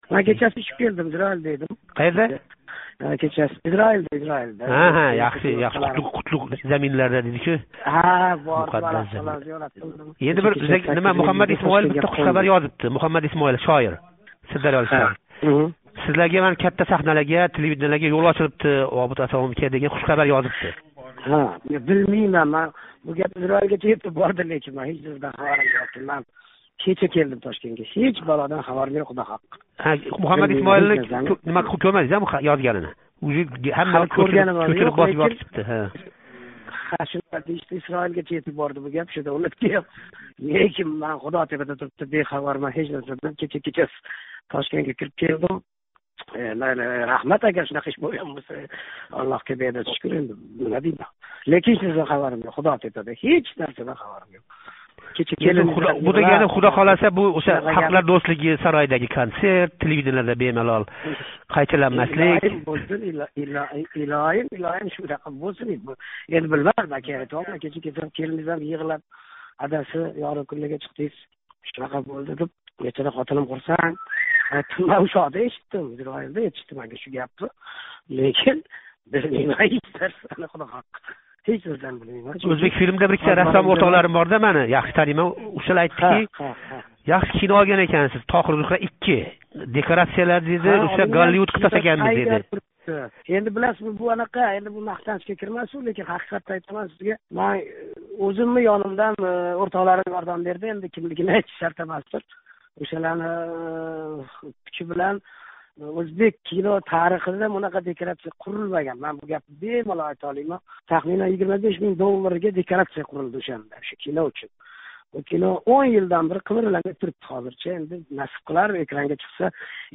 Обид Асомов билан суҳбат